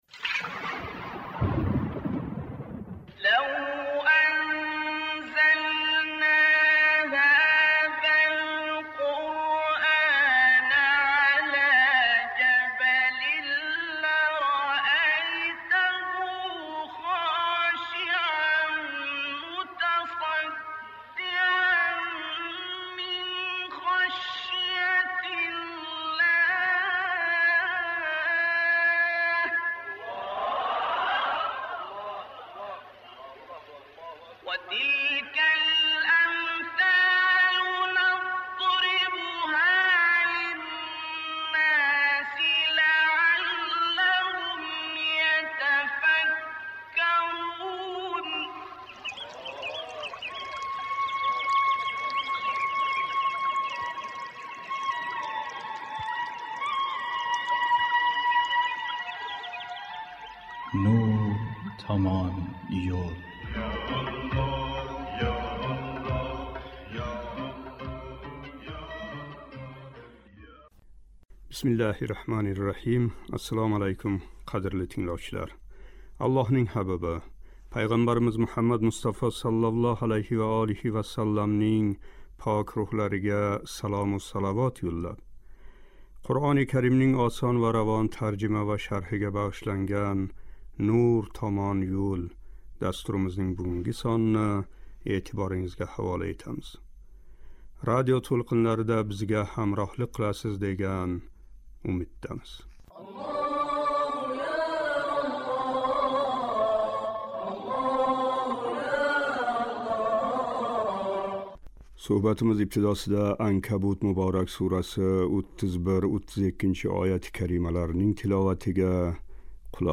Суҳбатимиз ибтидосида “Анкабут” муборак сураси 31-32--ояти карималарининг тиловатига қулоқ тутамиз.